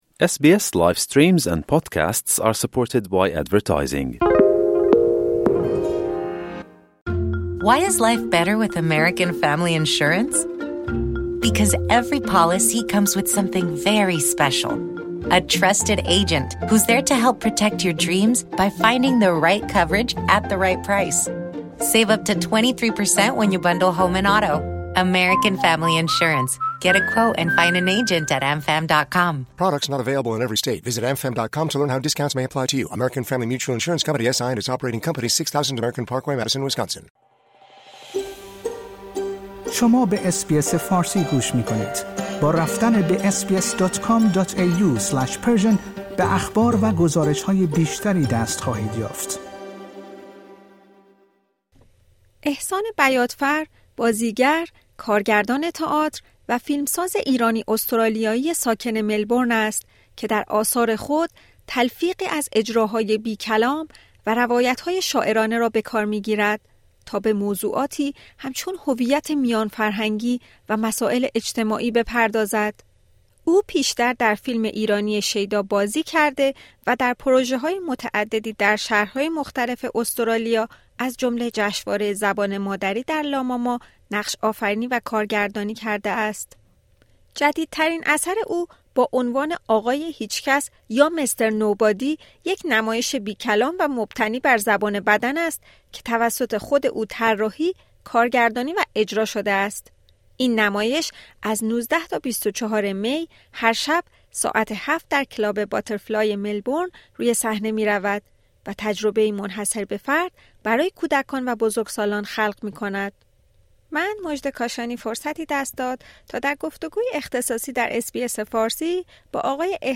در گفت‌وگویی با اس‌بی‌اس فارسی درباره نمایش «آقای هیچ‌کس» (Mr. Nobody)، چالش‌های اجرای آن و چگونگی شکل‌گیری ایده‌ این اثر توضیح می‌دهد.